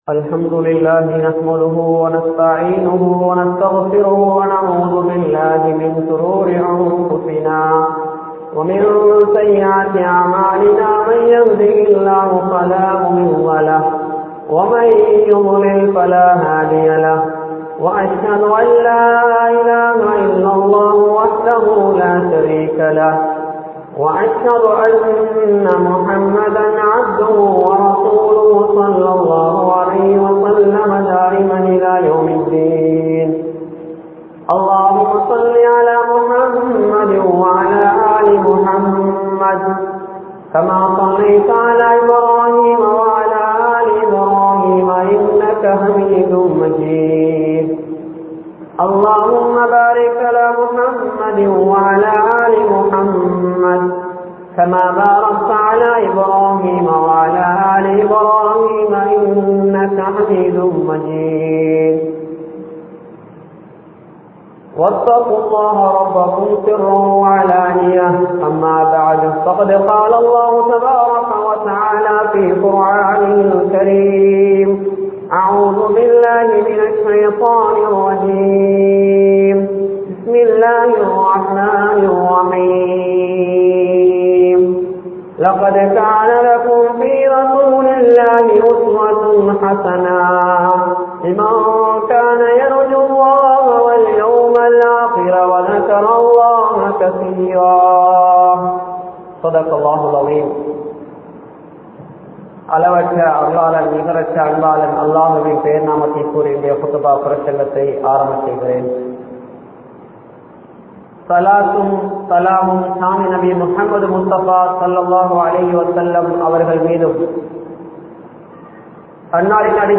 Sirantha Panpaadu (சிறந்த பண்பாடு) | Audio Bayans | All Ceylon Muslim Youth Community | Addalaichenai